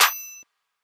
Clap (4).wav